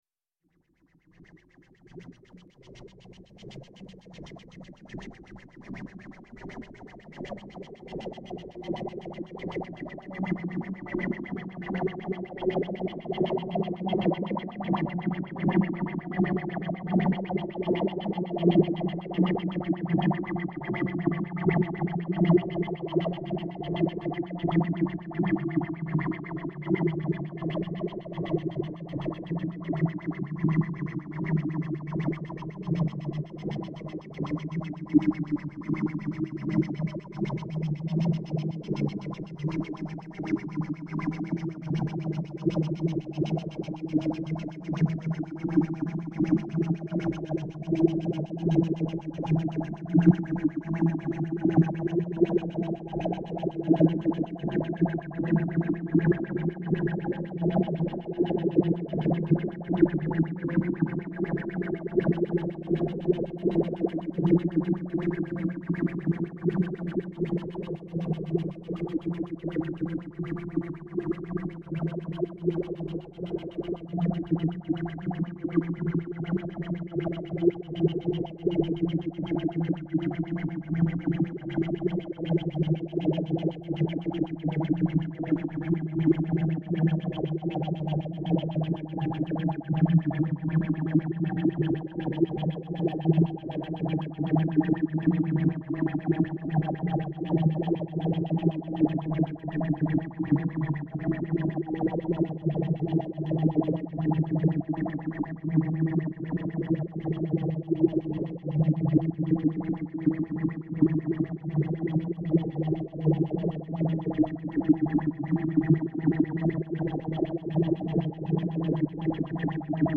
Hier gibt es meine Experimente mit Tönen und Geräuschen sowie diverser Hard- und Software zur Klangerzeugung.